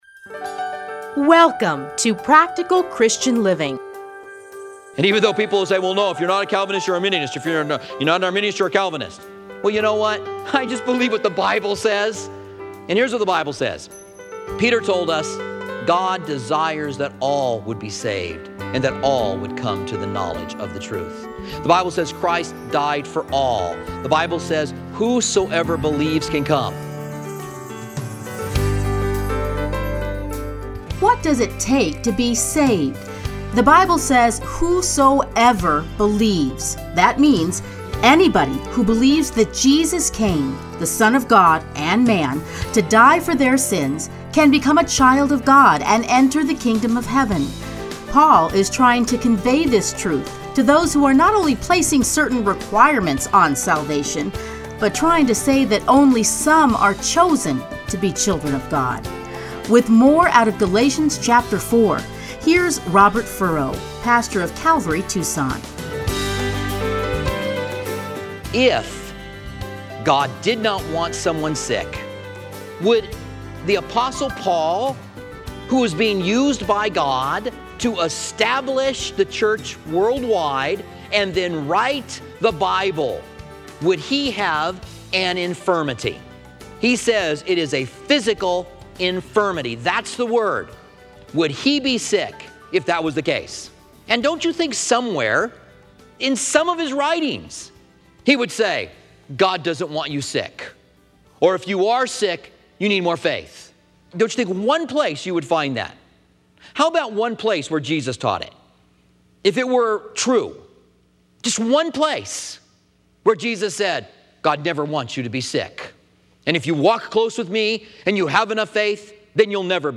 Listen here to his commentary on Galatians.